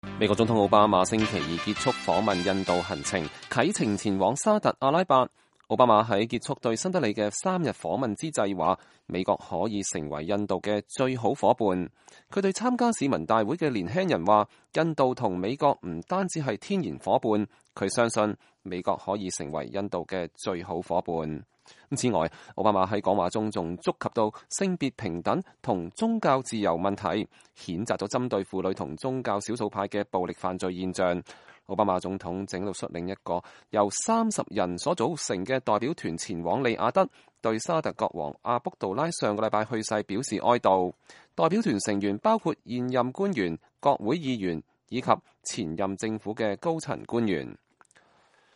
奧巴馬結束新德里訪問前發表演說